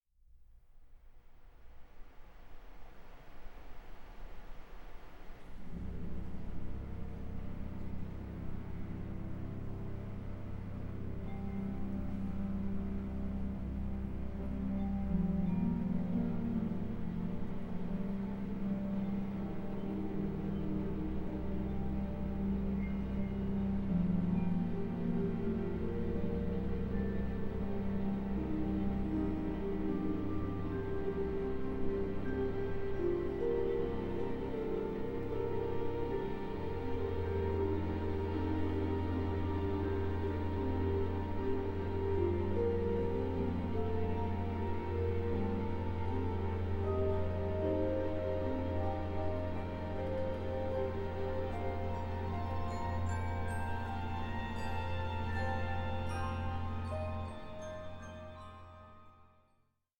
Aria narrativa